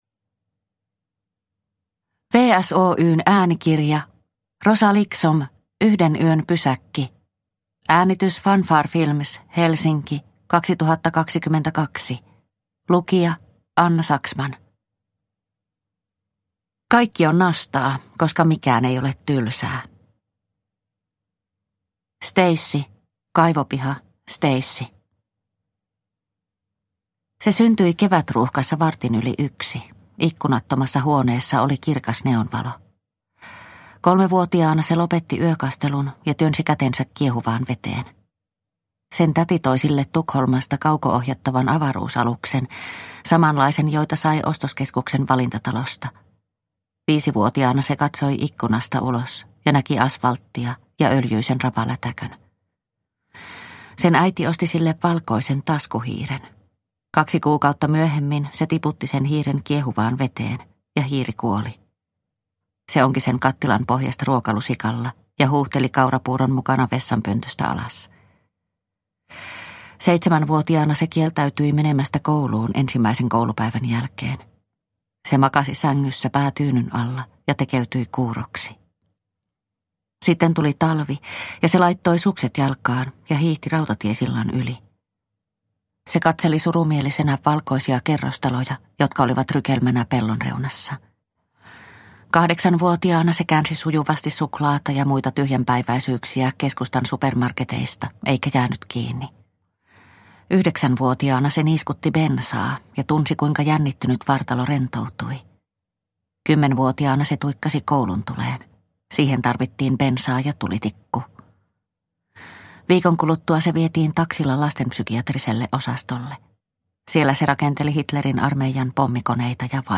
Yhden yön pysäkki – Ljudbok